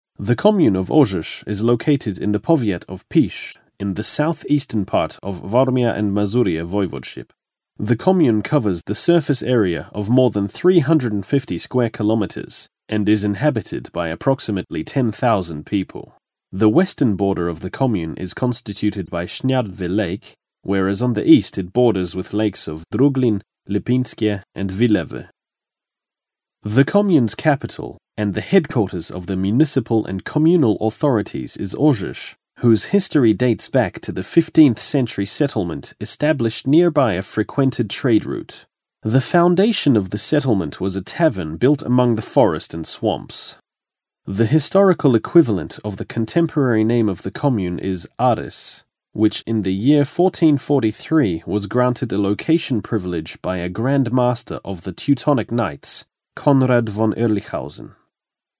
Posiada łatwość wymowy polskich nazw co jest zaletą podczas nagrań audioprzewodników oraz prezentacji w których występują polskie nazwiska.
Próbka: Nagranie lektorskie